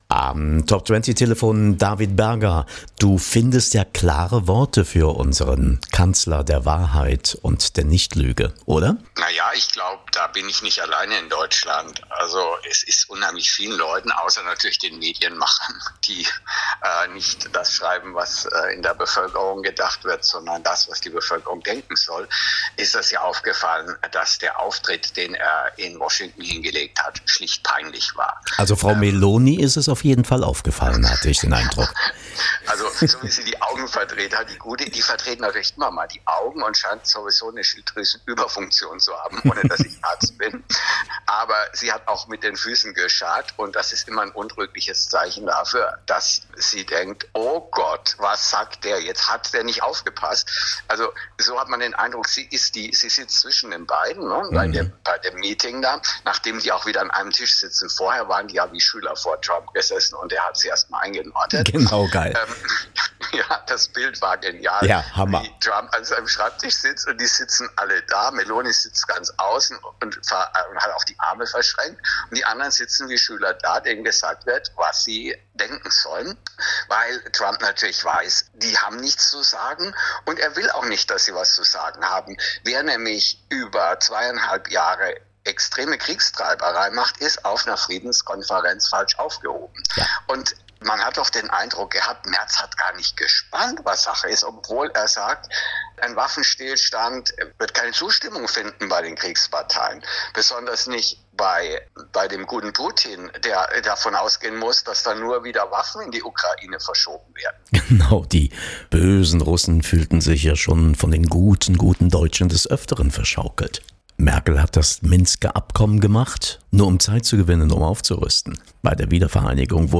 Interview-TOP20-Radio.mp3